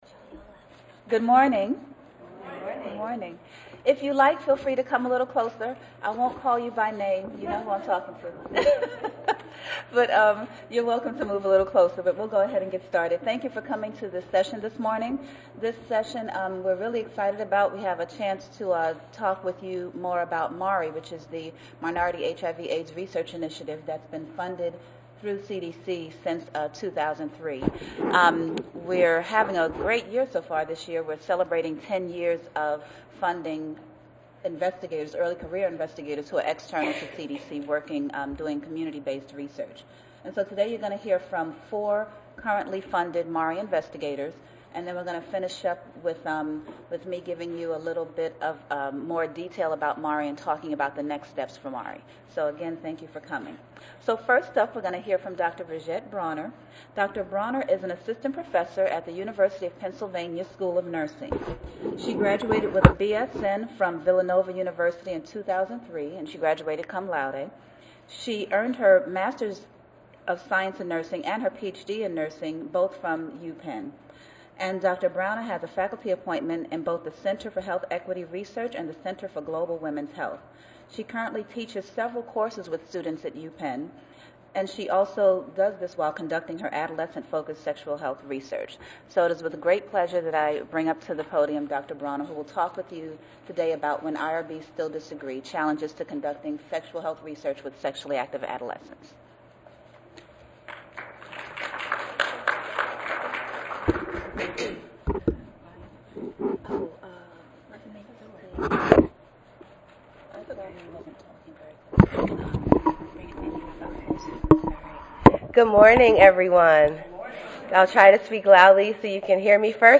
Oral MARI investigators from the University of Puerto Rico, University of Pennsylvania, University of Alabama at Tuscaloosa, and Emory University will deliver oral presentations related to their ongoing HIV prevention research in Black and Hispanic communities.